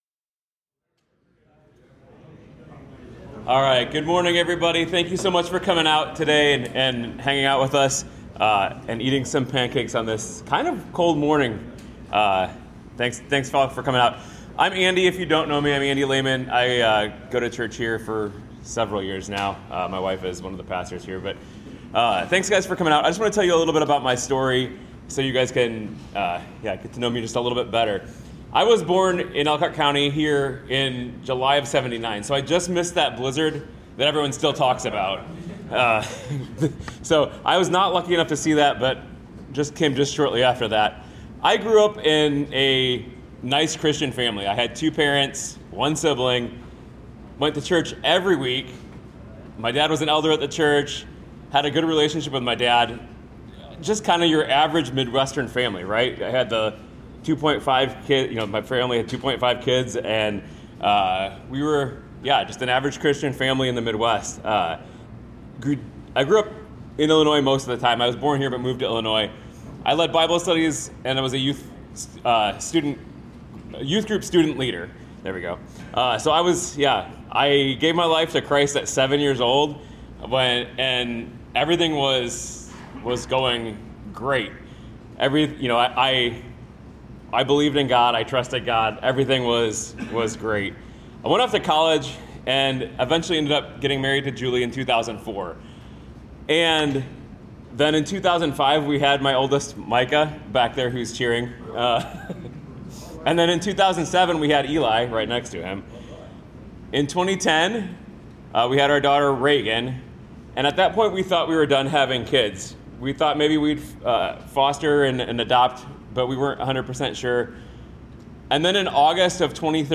In this episode I talk about my story and the loss our our daughter at a men's breakfast.